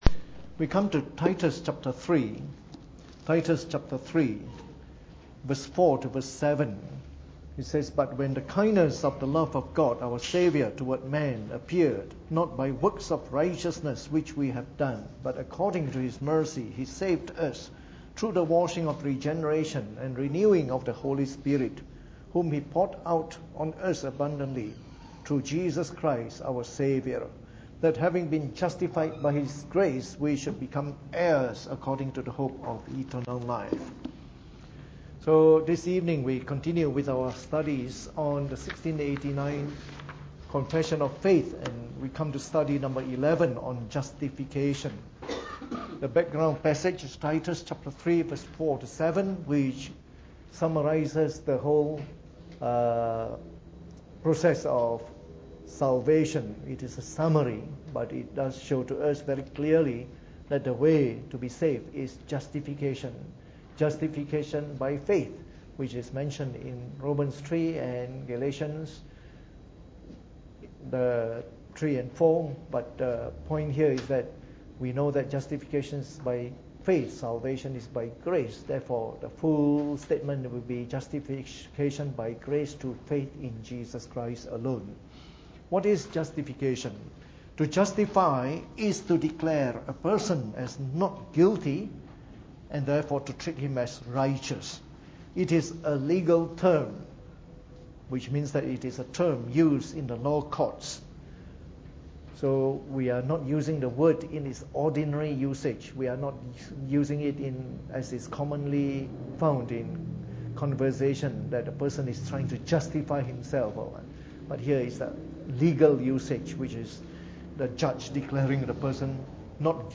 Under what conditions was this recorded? Preached on the 4th of May 2016 during the Bible Study, from our series on the Fundamentals of the Faith (following the 1689 Confession of Faith).